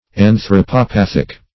Search Result for " anthropopathic" : The Collaborative International Dictionary of English v.0.48: Anthropopathic \An`thro*po*path"ic\, Anthropopathical \An`thro*po*path"ic*al\, a. Of or pertaining to anthropopathy.
anthropopathic.mp3